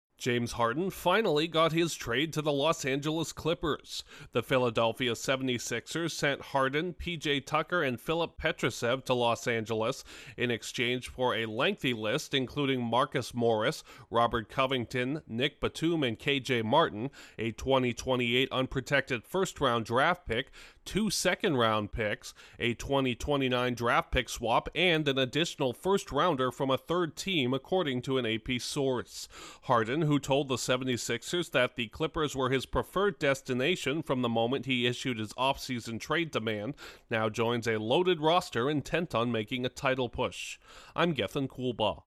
The 76ers have traded disgruntled star guard James Harden to the Los Angeles Clippers, per an AP source. Correspondent